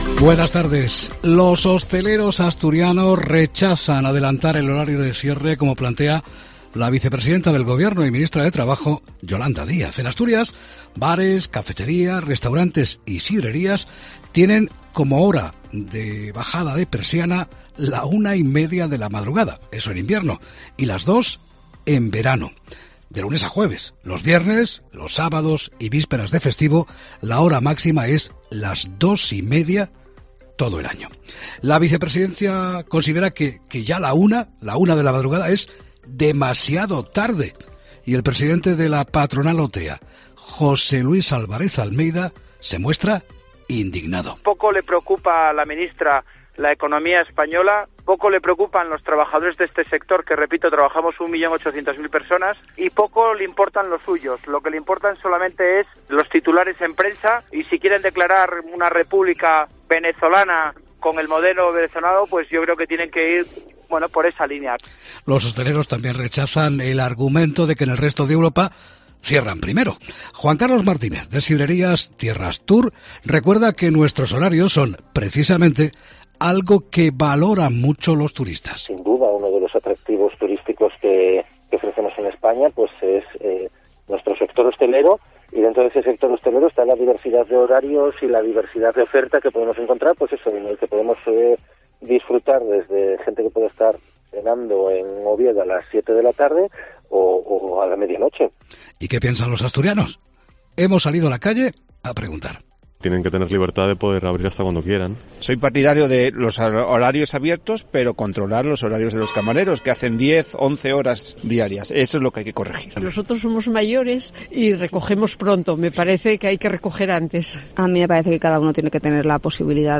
En una rápida encuesta a pie de calle la mayoría rechaza una reducción de horarios.